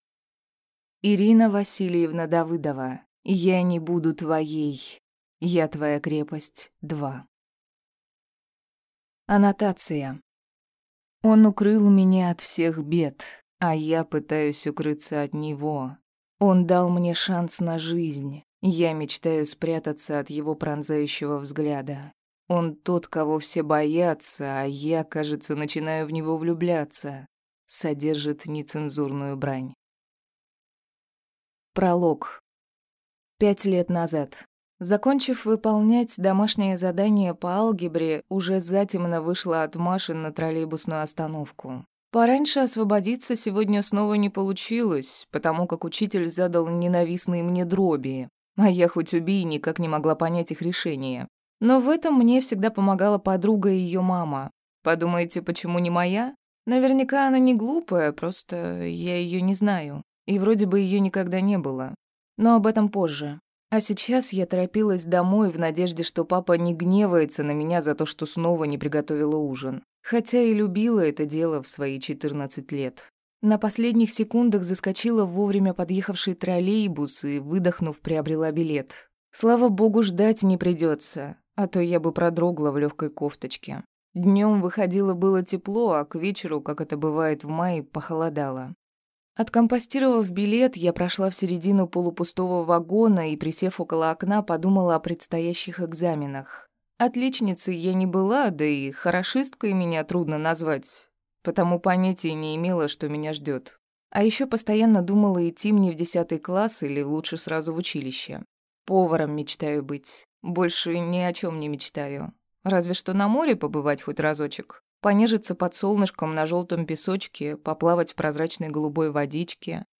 Аудиокнига Я не буду твоей | Библиотека аудиокниг
Прослушать и бесплатно скачать фрагмент аудиокниги